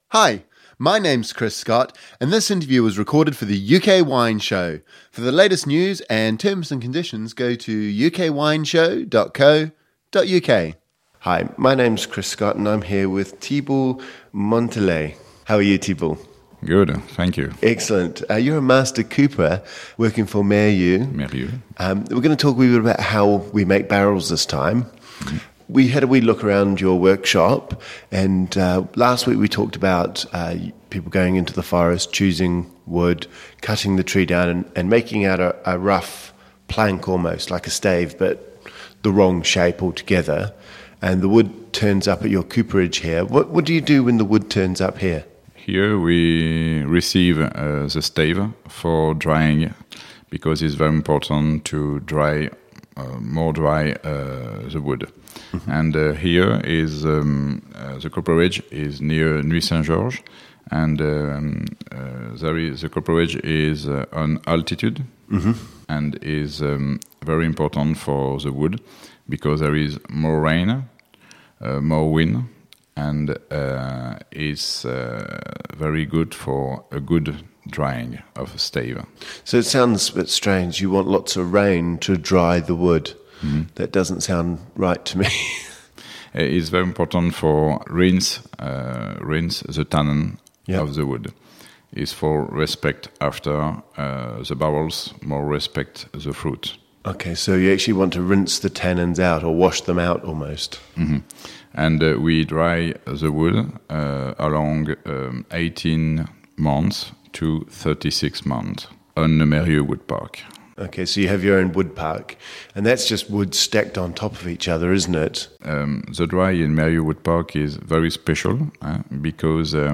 The oak staves can be toasted in a number of ways to produce different types of barrels for different styles of wines. The Meyrieux cooperage produces 3 types of barrels - the Traditional, the Grand Selection and the Premier Cru. A great interview on all aspects of barrel making.